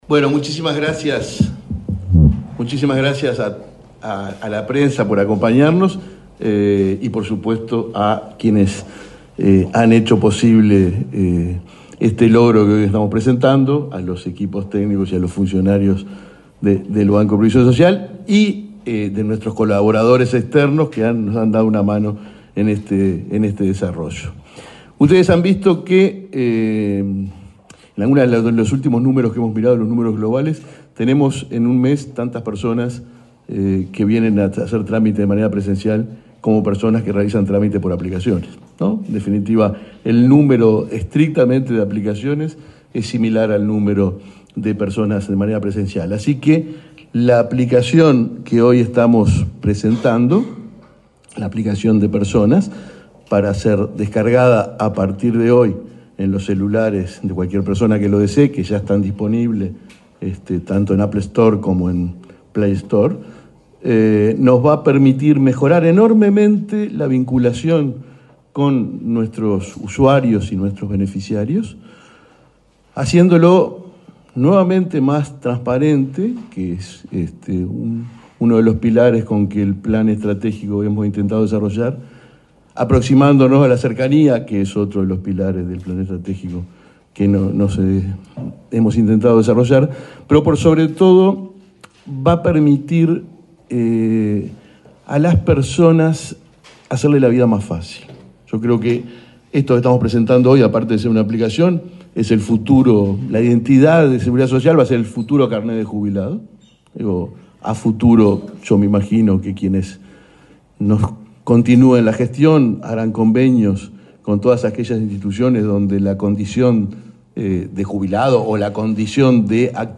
Declaraciones del presidente del BPS, Alfredo Cabrera
El presidente del Banco de Previsión Social (BPS), Alfredo Cabrera, dialogó con la prensa, luego de participar en la presentación de la aplicación BPS